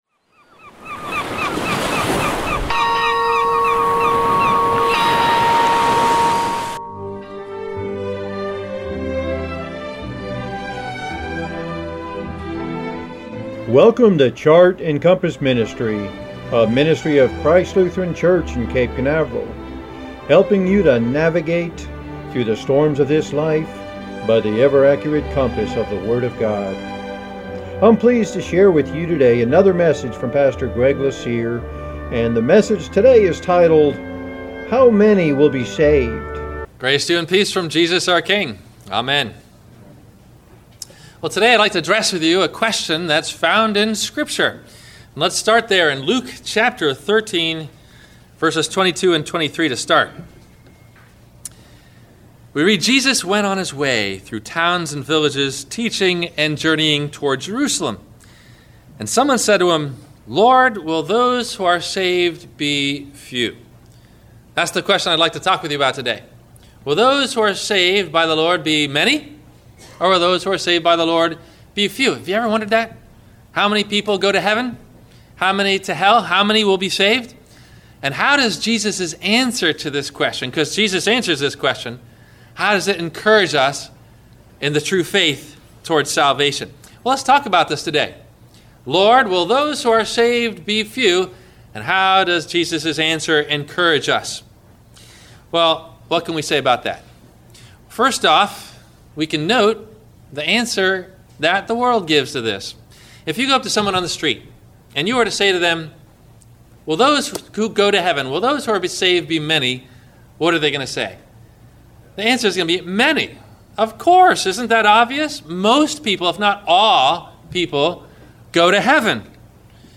How Many Will Be Saved? – WMIE Radio Sermon – May 11 2020